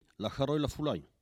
Saint-Jean-de-Monts
Catégorie Locution